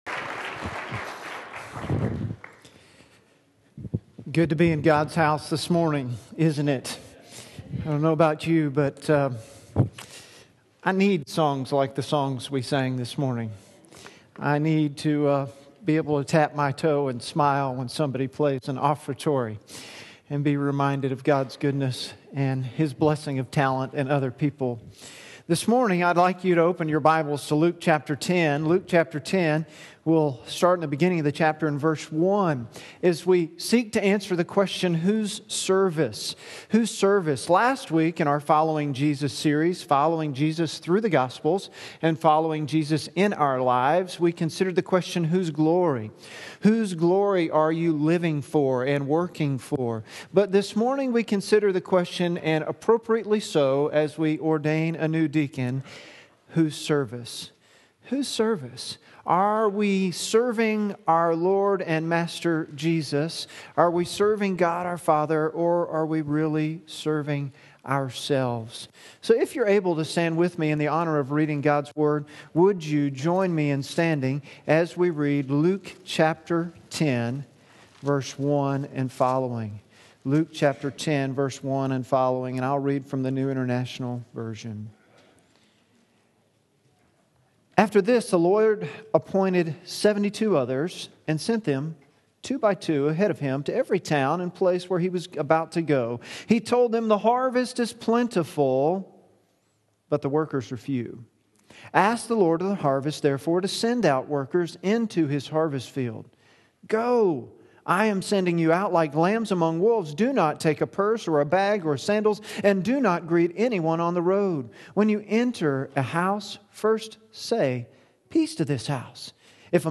Luke 10:1-24 Sermon notes on YouVersion Following Jesus: Whose Service?